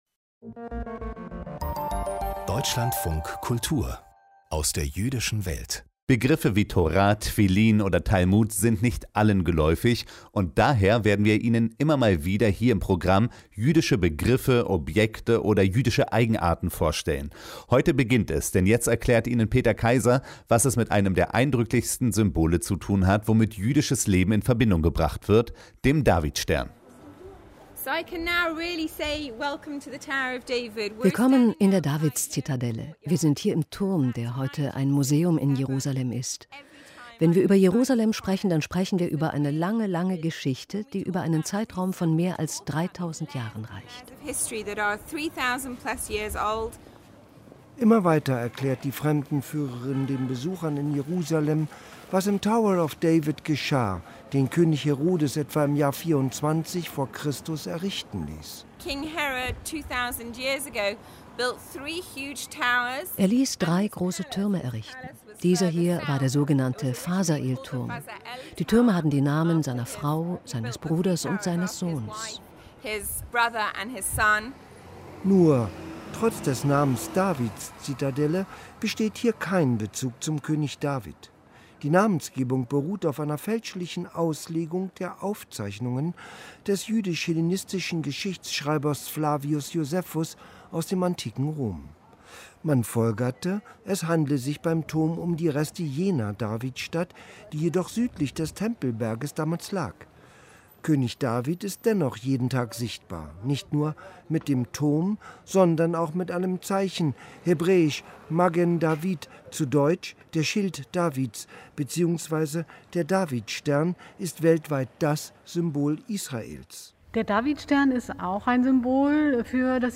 Kulturnachrichten - 10.06.2022